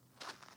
Sand Foot Step 1.wav